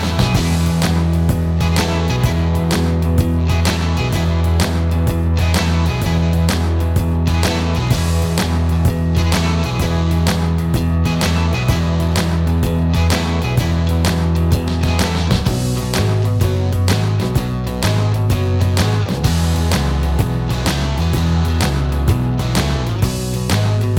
no Backing Vocals Glam Rock 3:16 Buy £1.50